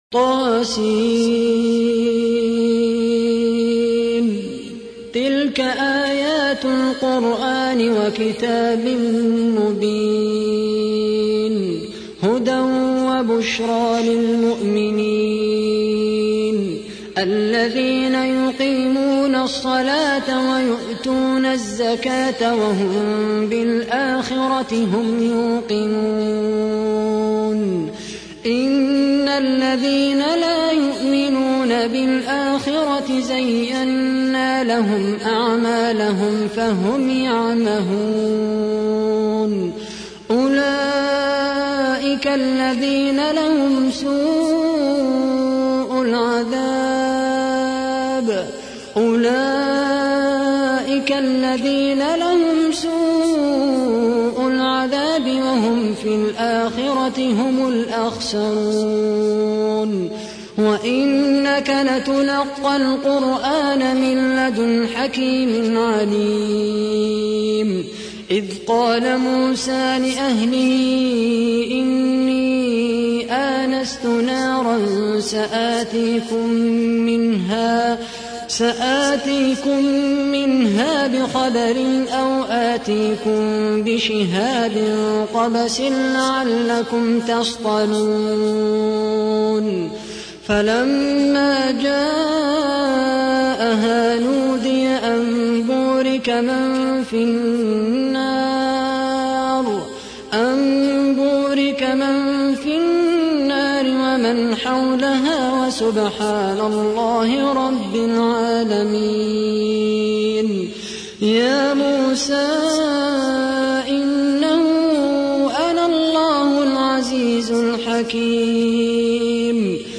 تحميل : 27. سورة النمل / القارئ خالد القحطاني / القرآن الكريم / موقع يا حسين